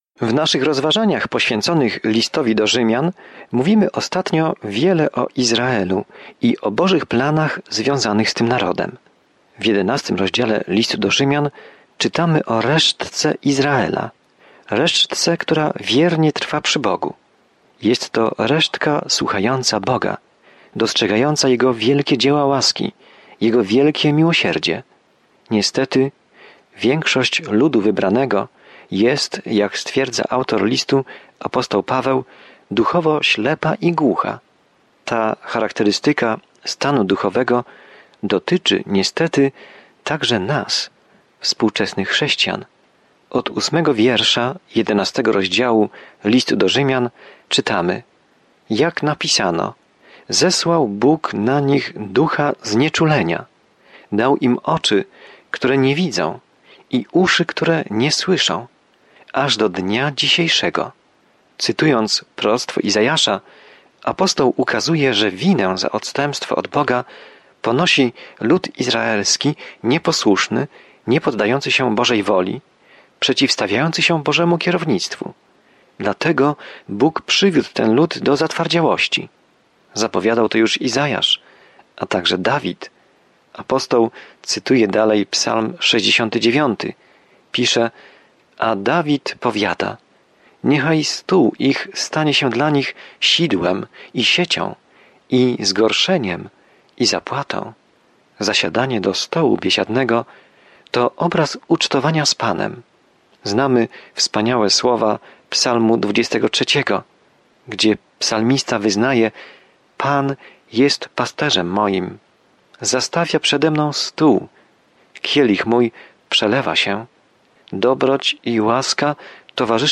Pismo Święte Rzymian 11:8-29 Dzień 25 Rozpocznij ten plan Dzień 27 O tym planie List do Rzymian odpowiada na pytanie: „Jaka jest dobra nowina?” I jak każdy może uwierzyć, zostać zbawiony, uwolniony od śmierci i wzrastać w wierze. Codzienna podróż przez List do Rzymian, słuchanie studium audio i czytanie wybranych wersetów słowa Bożego.